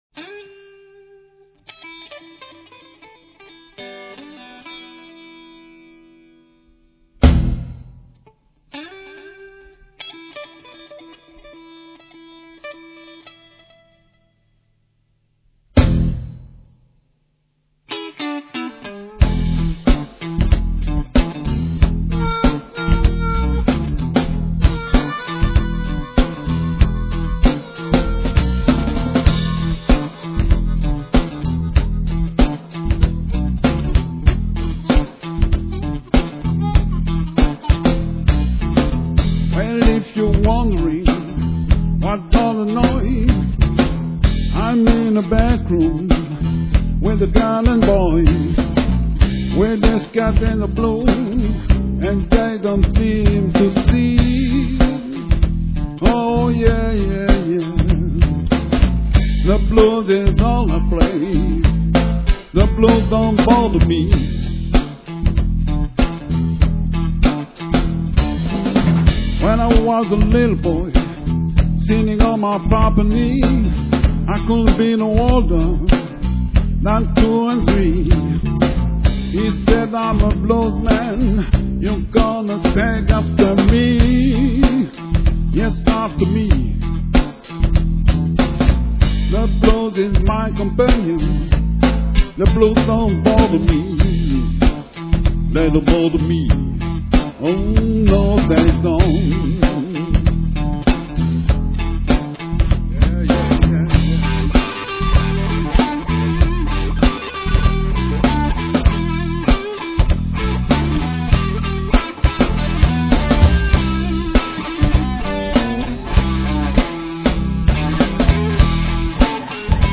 du répertoire Blues.
C'est du mp3, donc un peu compréssé,
d'ou la qualité moyenne du son…